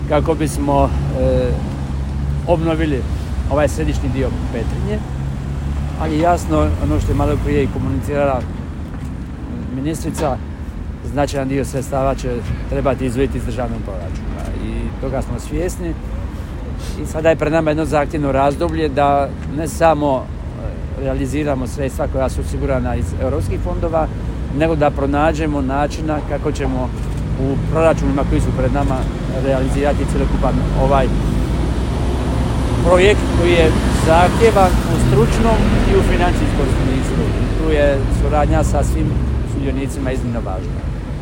Rokovi za iskorištenje sredstava iz Fonda solidarnosti su blizu. Očekujemo da sudionici naprave maksimalno koliko mogu u zadanim rokovima, rekao je u Petrinji potpredsjednik Vlade i resorni ministar Branko Bačić